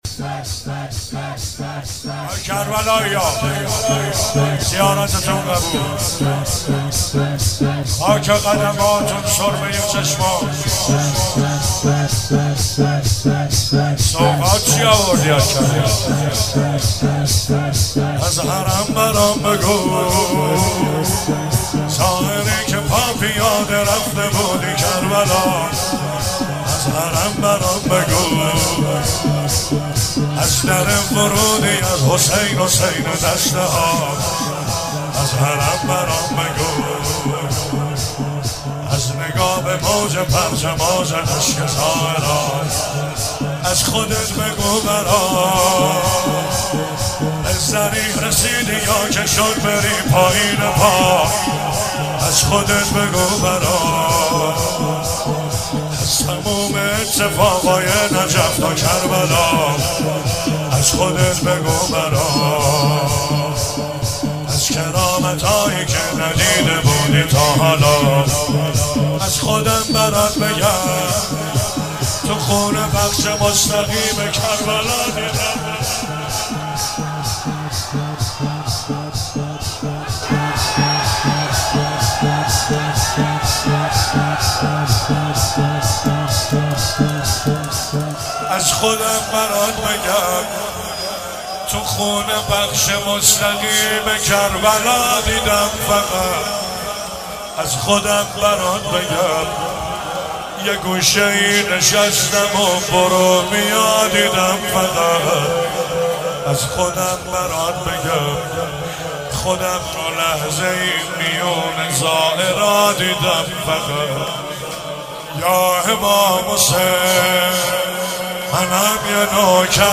شب سوم رمضان 95، حاح محمدرضا طاهری
05 heiat alamdar mashhad.mp3